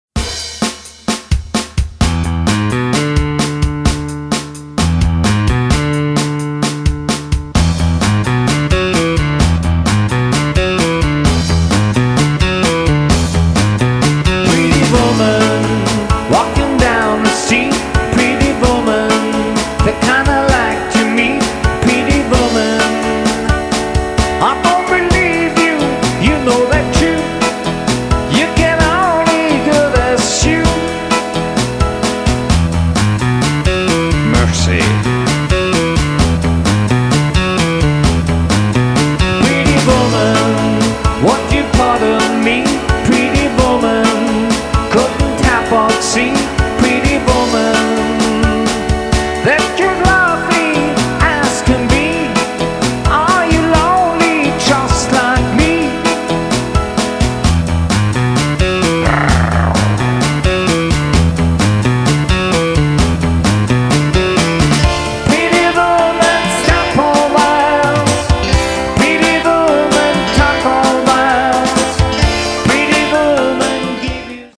Alleinunterhalter mit Keyboard & Gesang
KEYBOARD & GESANG